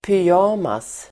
Ladda ner uttalet
Uttal: [pyj'a:mas]
pyjamas.mp3